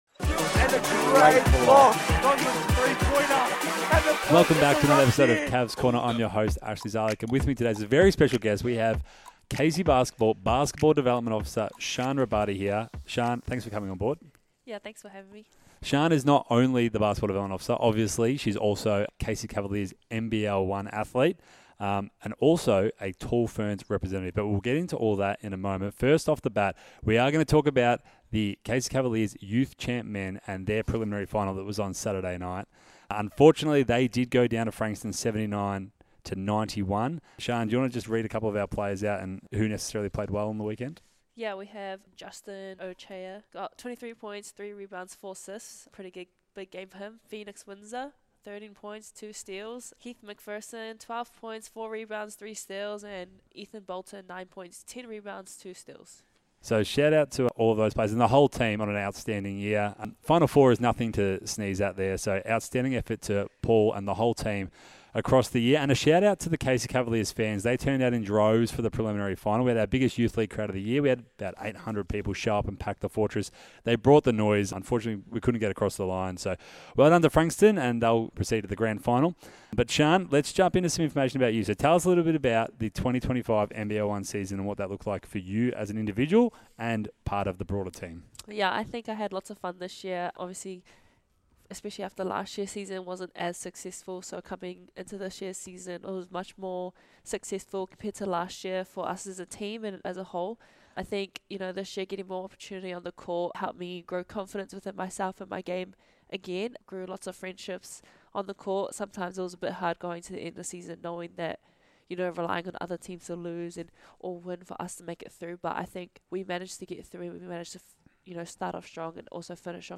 Cavs Corner is a weekly podcast that provides updates on the Casey Basketball Association in a light hearted discussion. Keep up to date on all Casey Cavaliers news, including regular recaps on the NBL1 Casey Cavaliers!